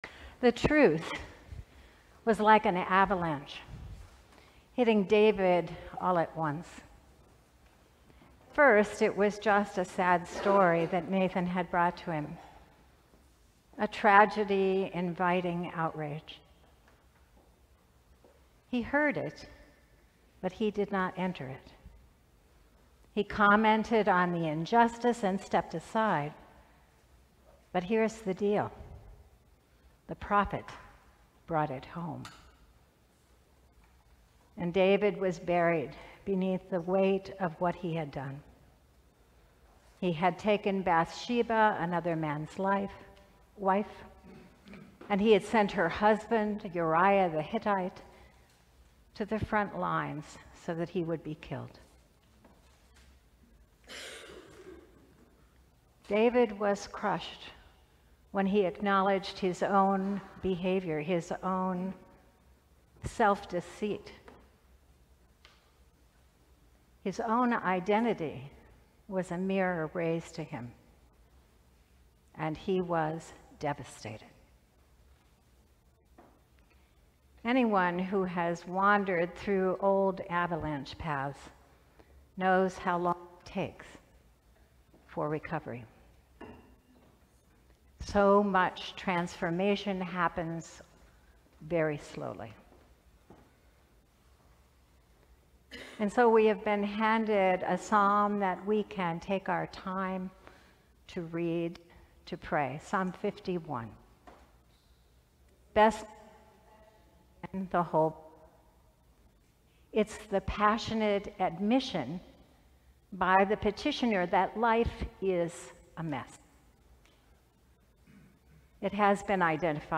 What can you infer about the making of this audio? The Eleventh Sunday after Pentecost We invite you to join us in worship at St. […] Sermons from St. John's Cathedral